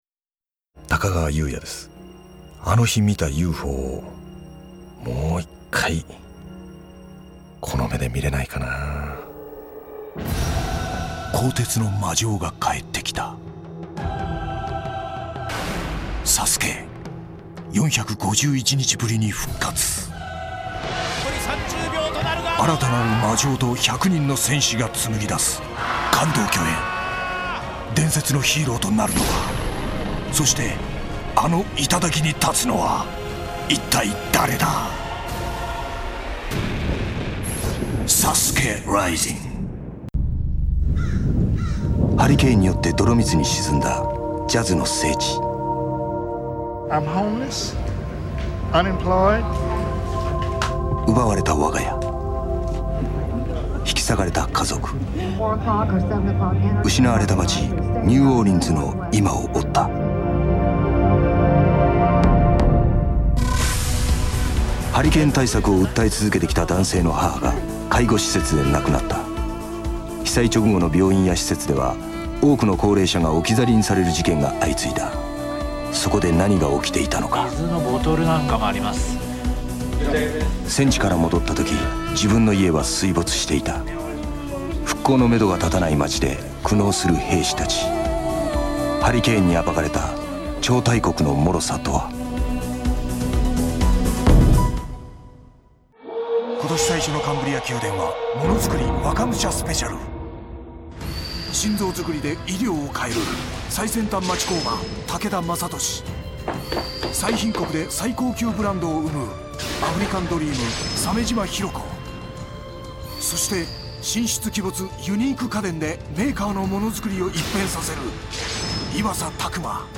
高川さんは俳優としても長年活躍されていますが演出家でもありナレーターでもあります。
高川裕也さんのボイスサンプル
イケメンの上にイケボなんですねー。